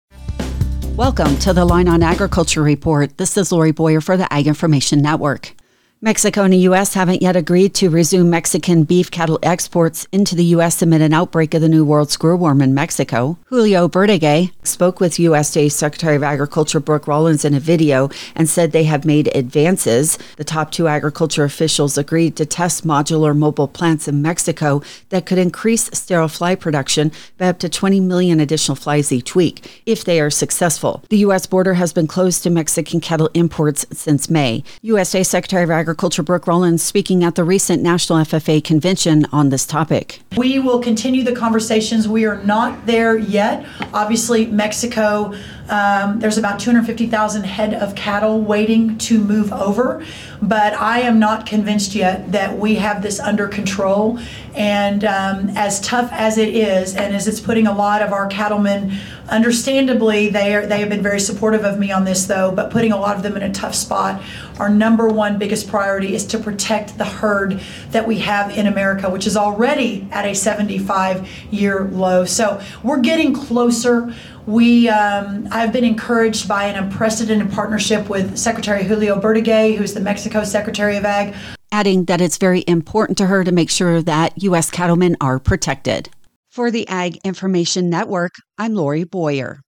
Reporter
USA Secretary of Agriculture, Brooke Rollins, speaking at the recent National FFA Convention on this topic.